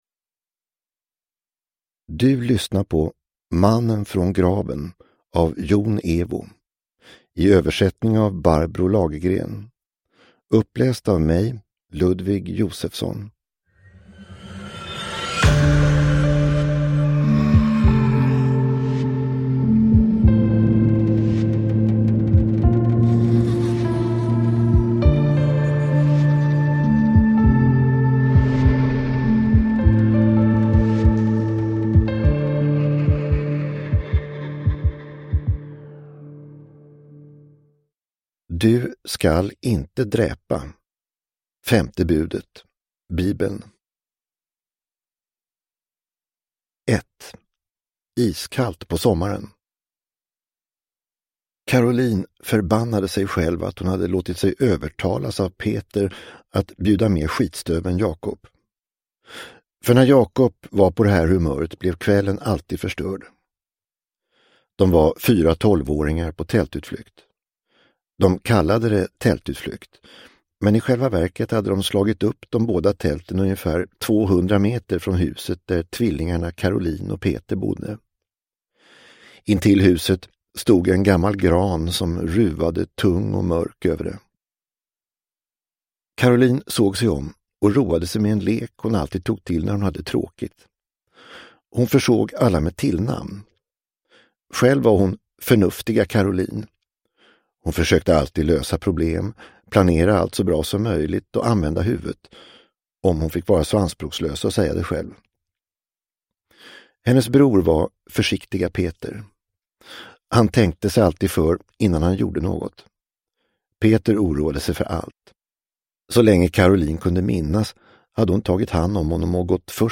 Mannen från graven – Ljudbok – Laddas ner